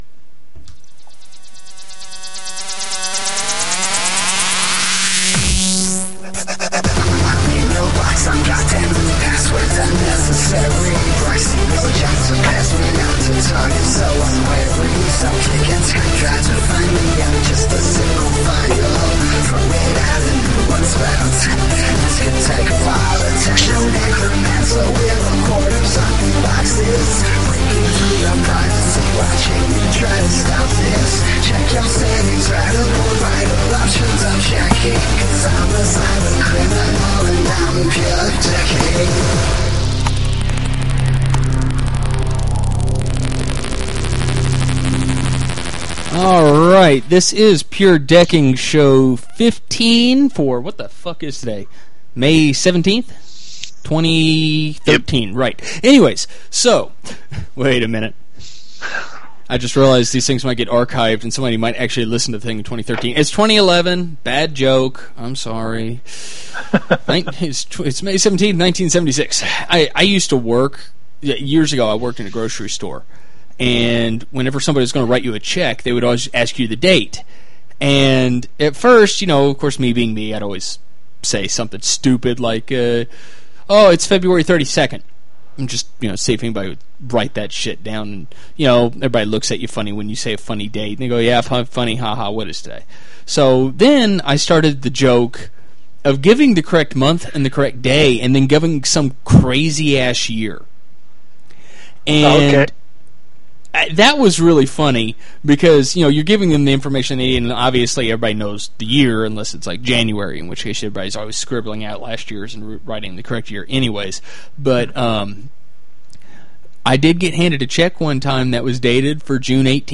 live show